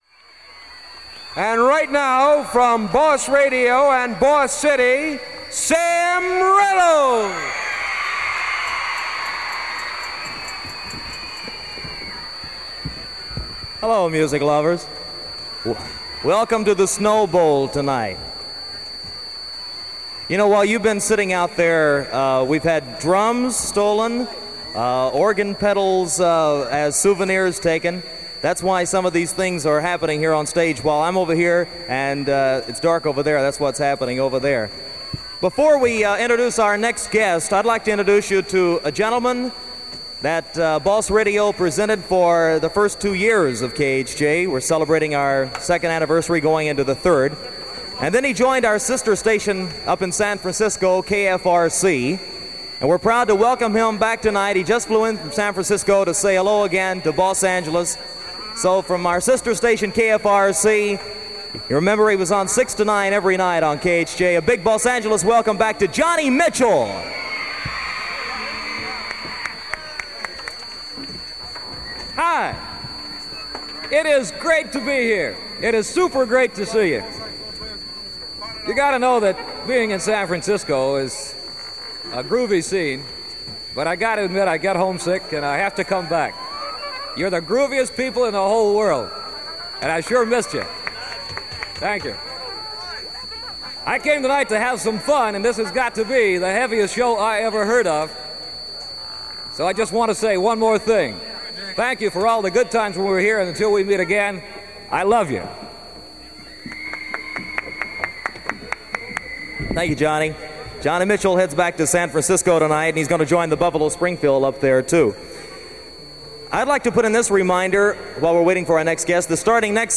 In Concert